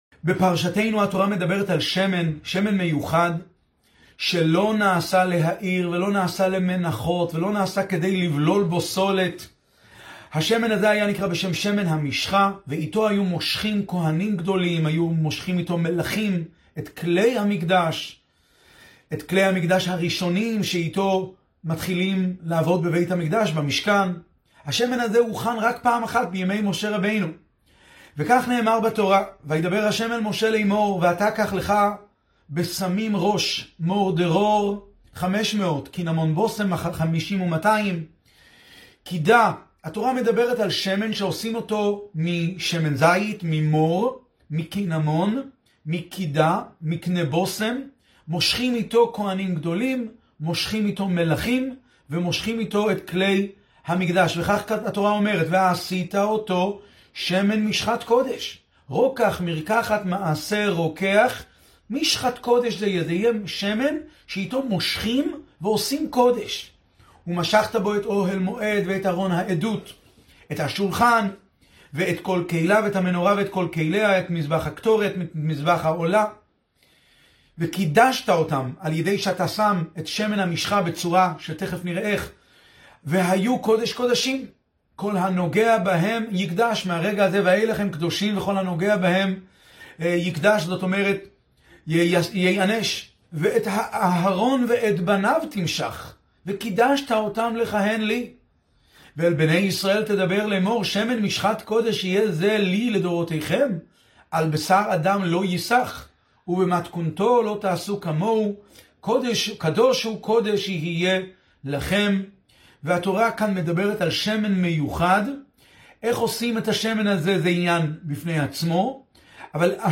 שיעור בעיון עברית